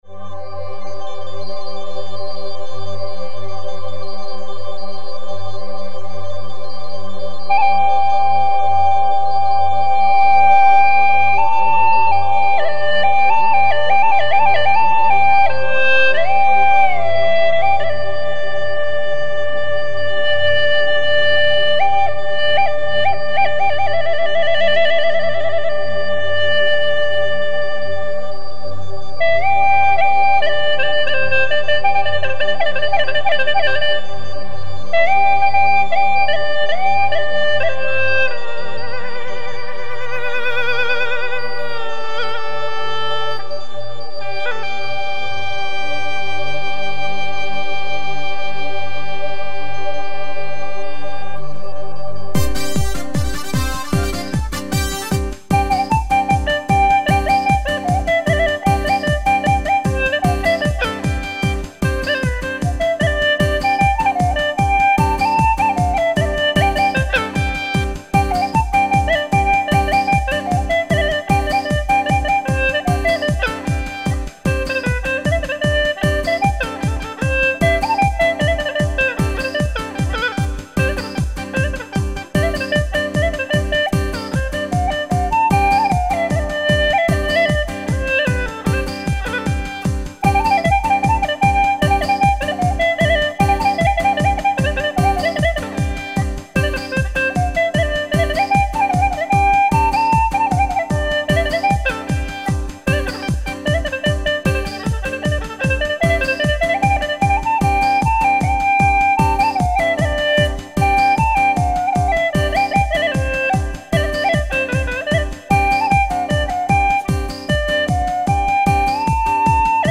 调式 : C 曲类 : 独奏